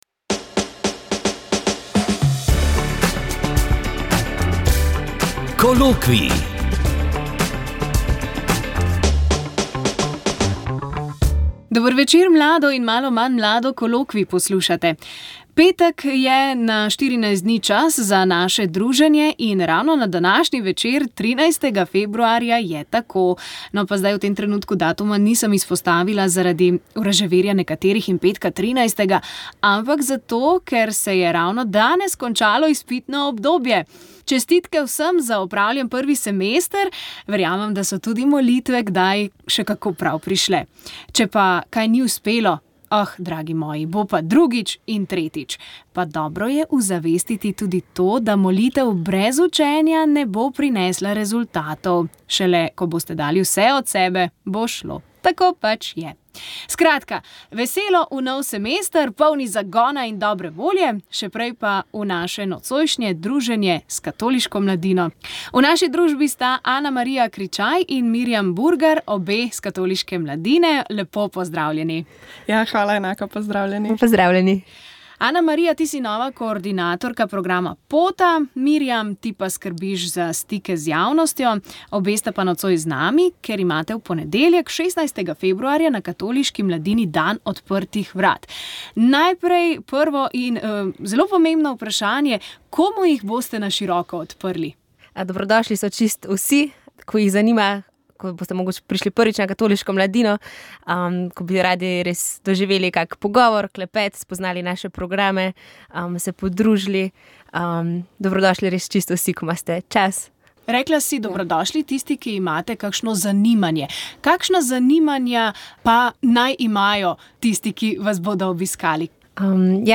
Govor dramskega igralca Gregorja Čušina